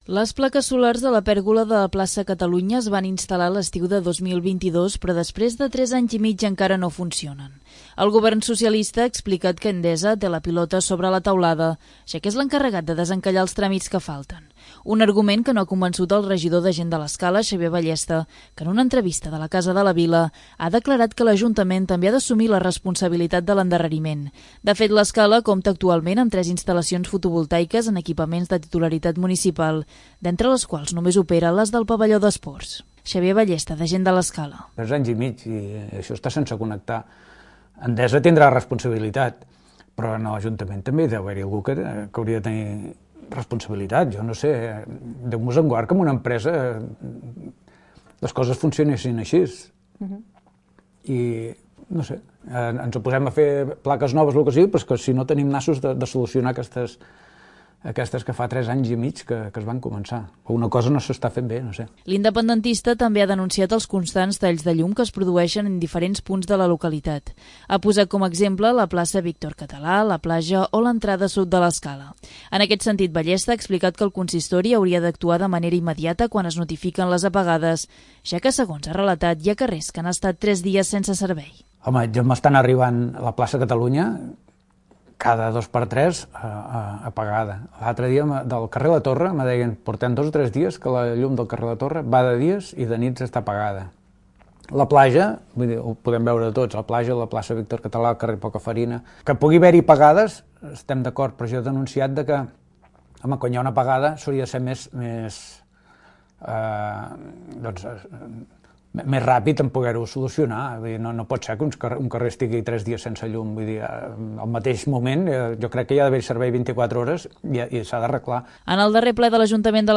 Un argument que no ha convençut el regidor de Gent de l'Escala, Xavier Ballesta, que en una entrevista de 'La Casa de la Vila', ha declarat que l'ajuntament també ha d'assumir la responsabilitat de l'endarreriment.